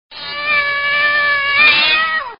Cat Meow Download
cat.MP3